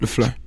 TS - CHANT (16).wav